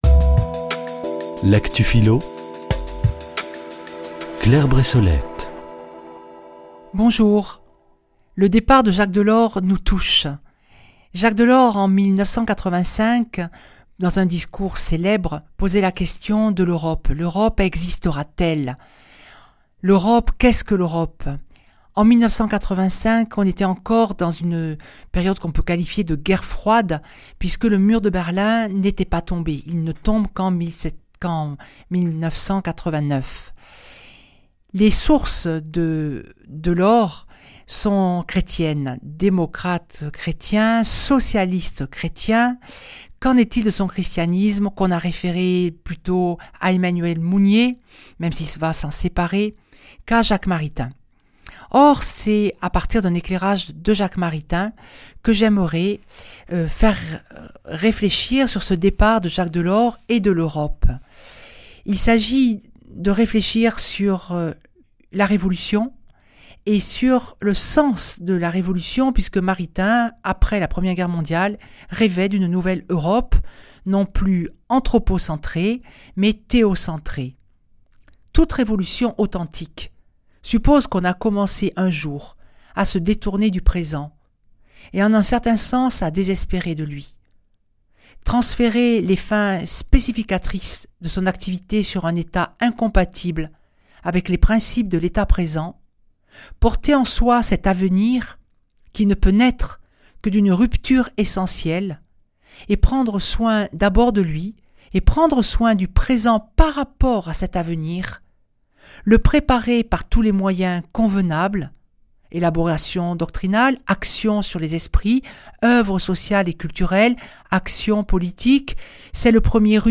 Chroniqueuse